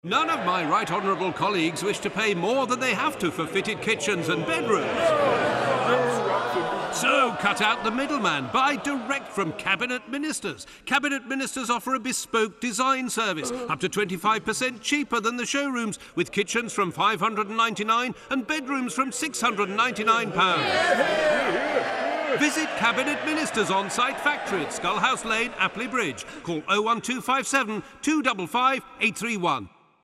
Character and Cartoon voices